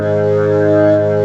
55O-ORG04-G#.wav